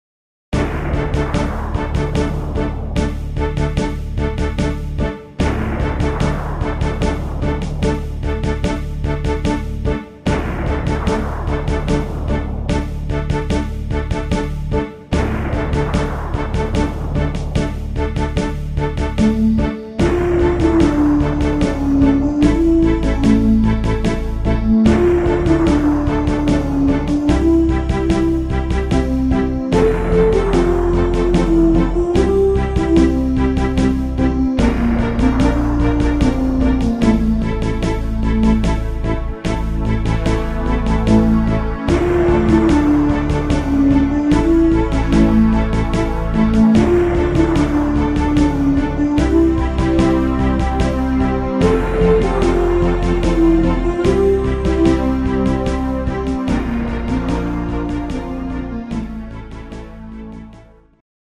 instumental Orchester